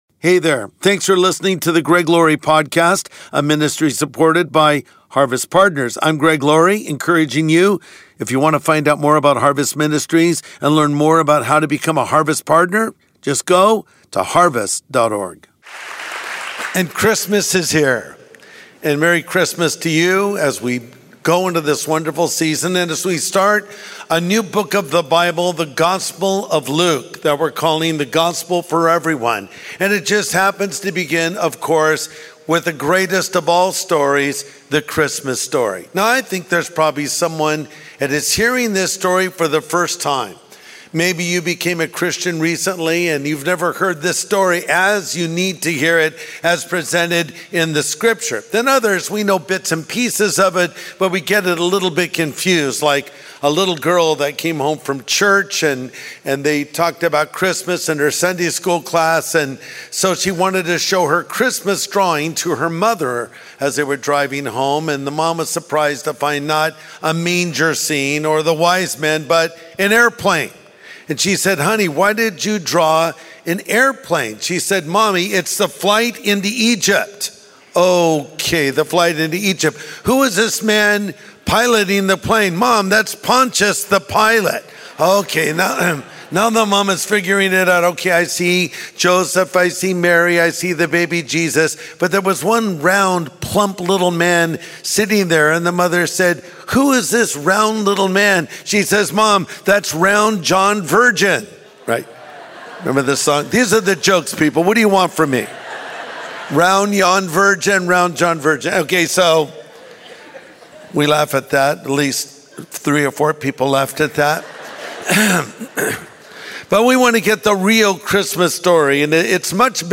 Christmas: The Story Before the Story | Sunday Message Podcast with Greg Laurie